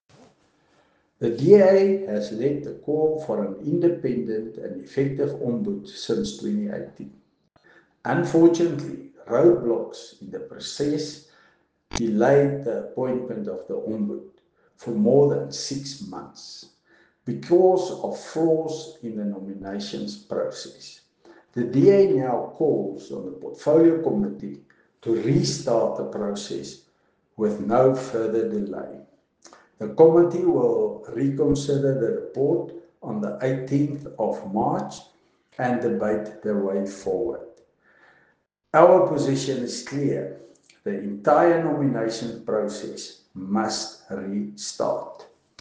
Afrikaans soundbites by Henro Kruger MP.